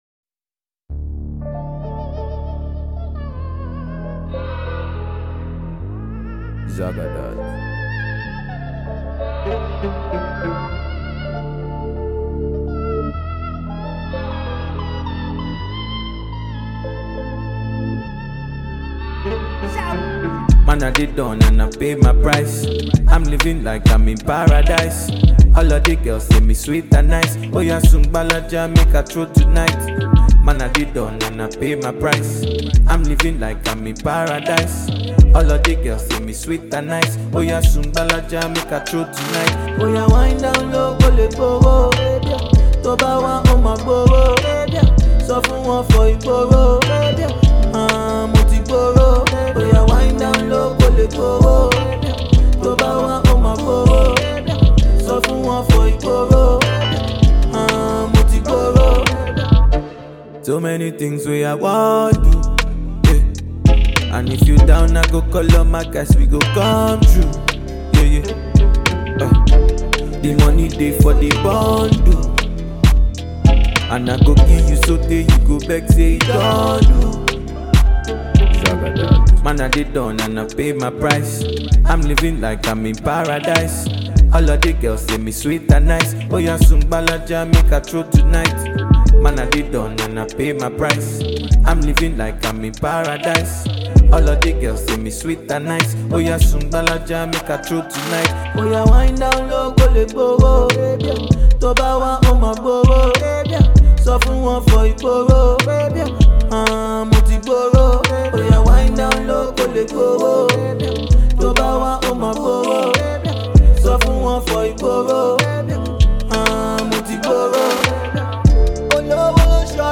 Afropop singer and songwriter
larger-than-life, opera-flavored afro-trap anthem